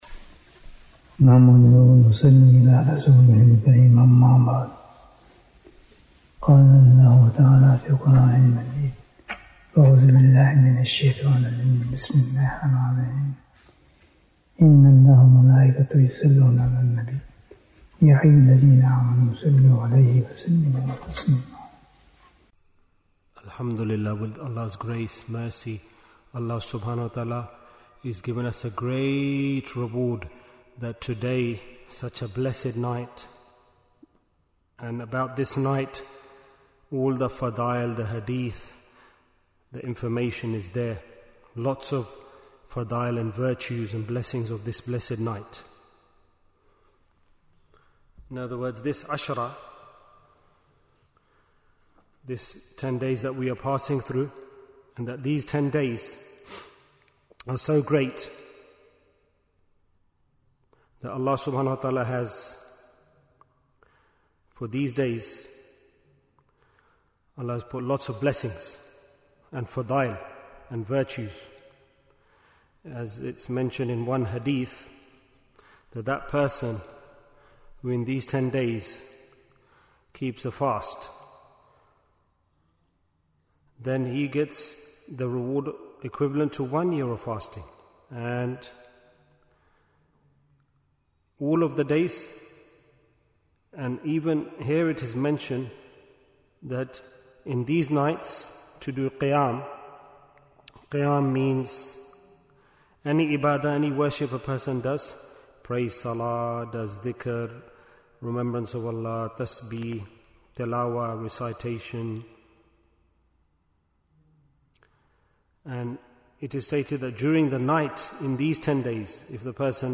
The Message of Arafah Bayan, 19 minutes4th June, 2025